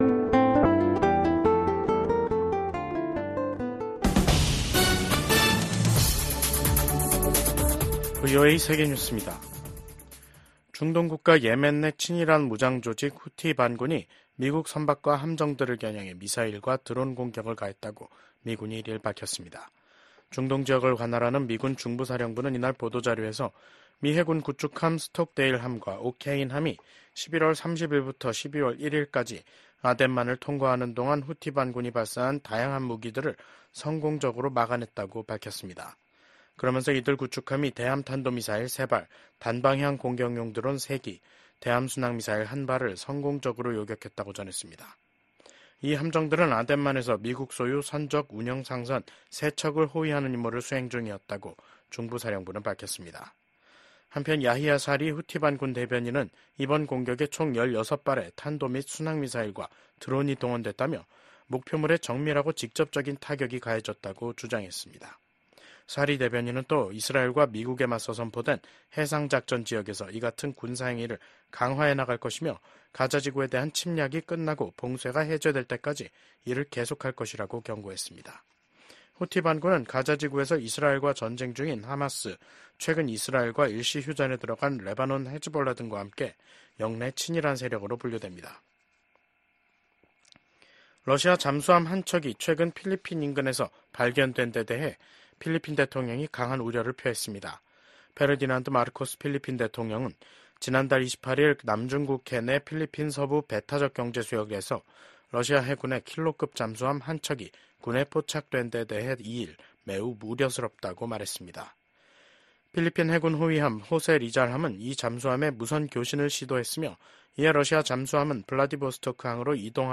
VOA 한국어 간판 뉴스 프로그램 '뉴스 투데이', 2024년 12월 2일 3부 방송입니다. 김정은 북한 국무위원장이 북한을 방문한 안드레이 벨로우소프 러시아 국방장관을 만나 우크라이나 전쟁과 관련해 러시아에 대한 지지 입장을 거듭 분명히 했습니다. 미국 국무부는 러시아 국방장관의 북한 공식 방문과 관련해 북러 협력 심화에 대한 우려 입장을 재확인했습니다.